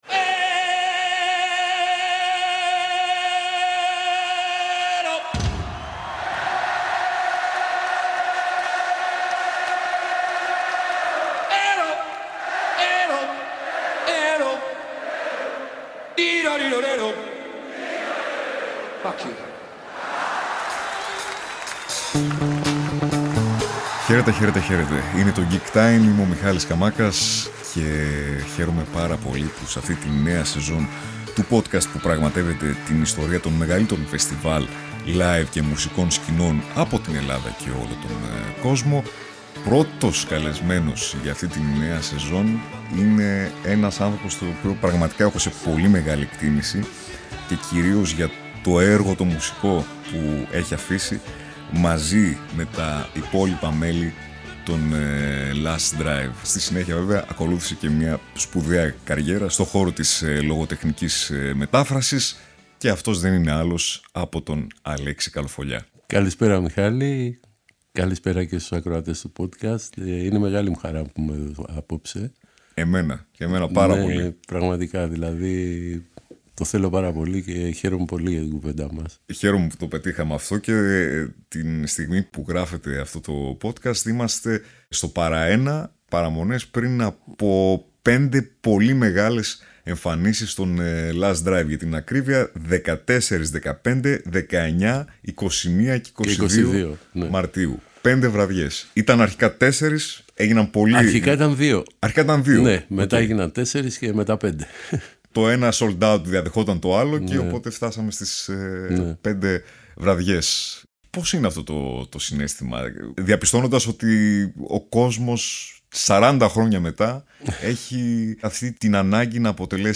Νέο επεισόδιο gig time με καλεσμένο στο στούντιο